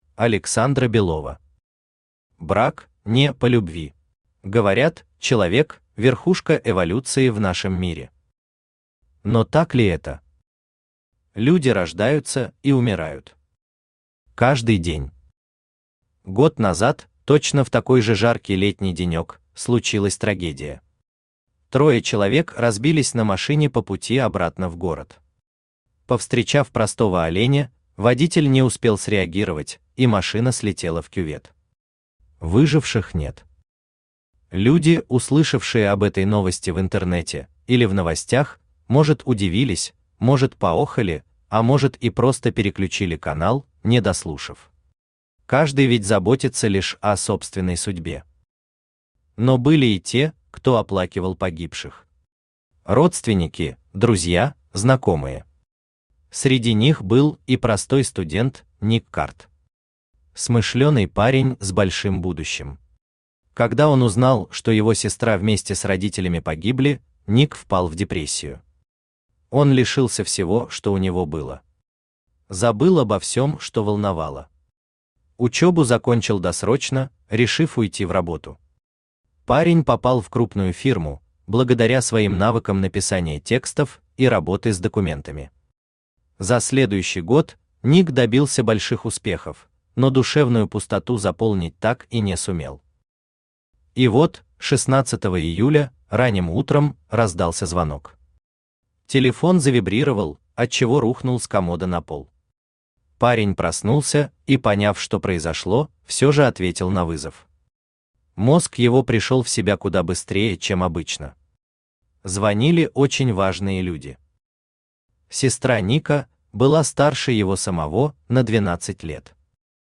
Аудиокнига Брак (не) по любви | Библиотека аудиокниг